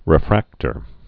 (rĭ-frăktər)